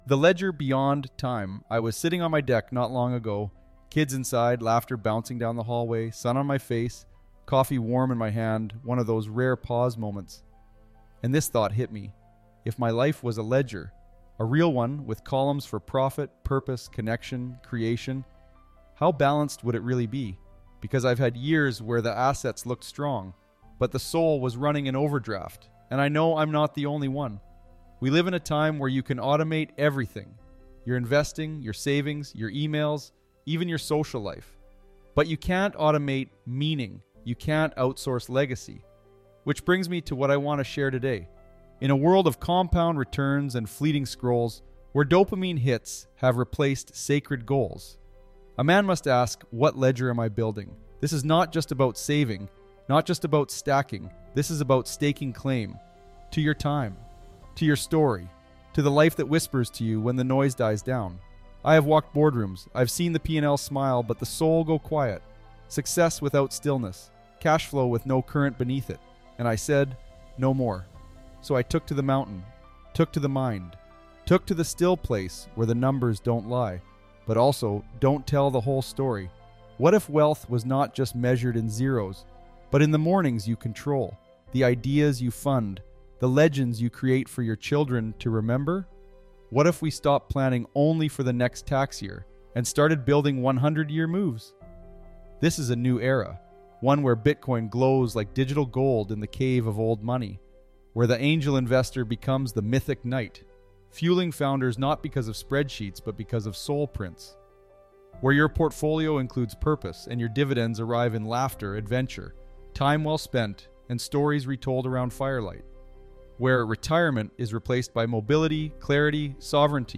laughter in the background—and poses a profound question